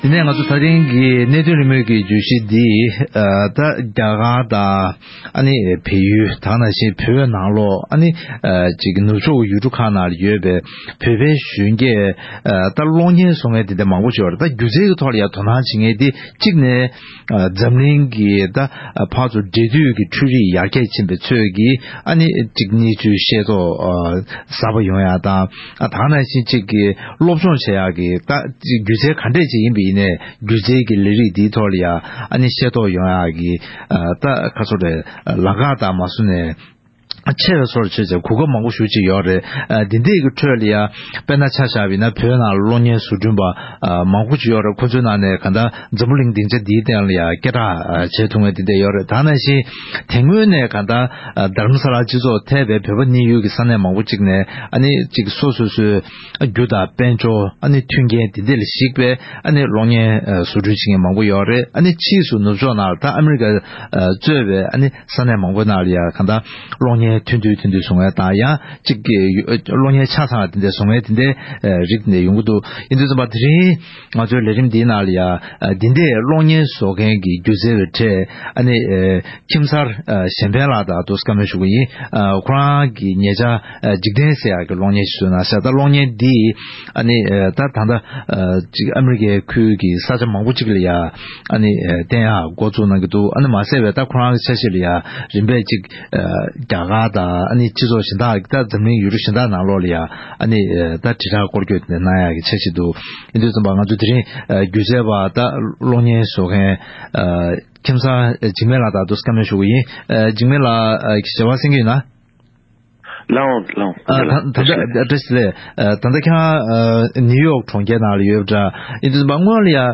གླེང་མོལ་ཞུས་པ།